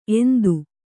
♪ endu